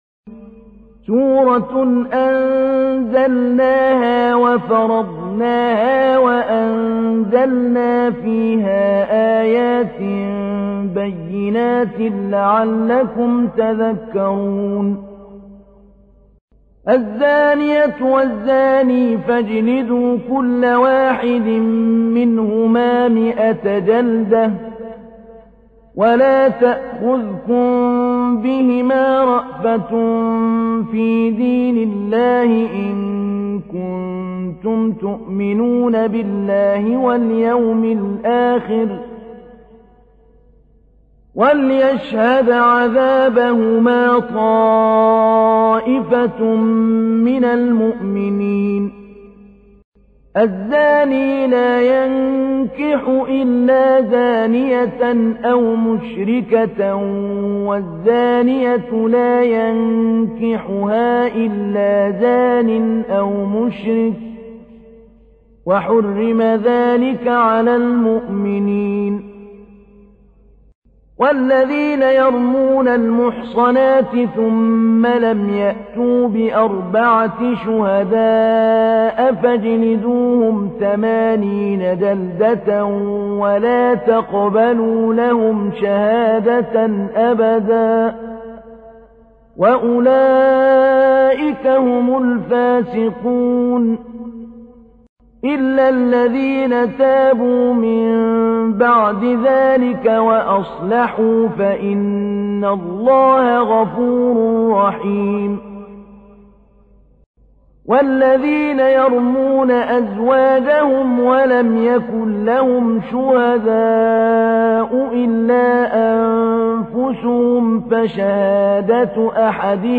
تحميل : 24. سورة النور / القارئ محمود علي البنا / القرآن الكريم / موقع يا حسين